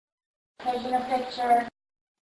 Harney Mansion EVP
The raw but slightly amplified EVP